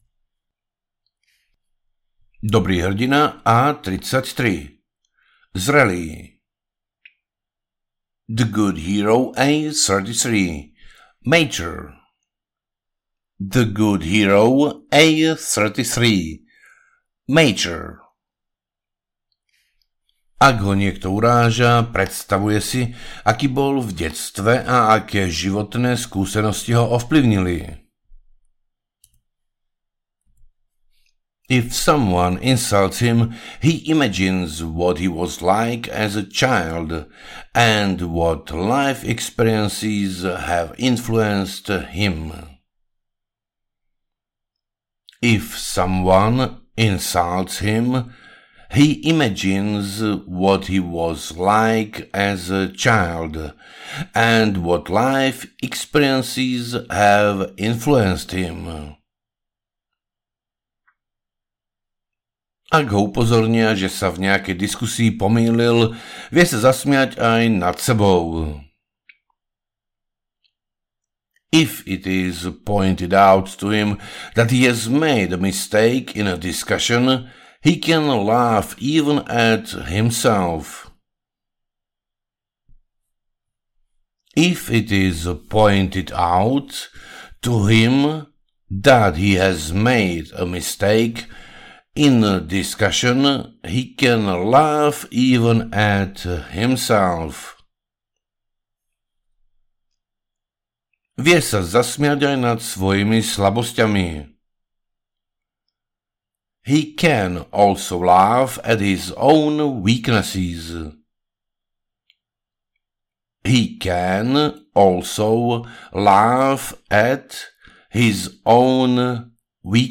Dobrí a zlí hrdinovia, EN, SK audiokniha
Ukázka z knihy